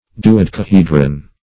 Search Result for " duodecahedron" : The Collaborative International Dictionary of English v.0.48: Duodecahedral \Du`o*dec`a*he"dral\, a., Duodecahedron \Du`o*dec`a*he"dron\, n. See Dodecahedral , and Dodecahedron .
duodecahedron.mp3